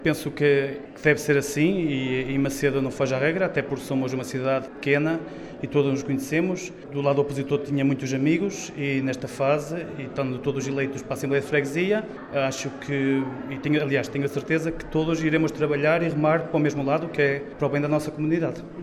No discurso de tomada de posse, David Vaz assegurou que quer trabalhar em conjunto com todos os membros do executivo, em prol dos habitantes de Macedo, Travanca e Nogueirinha: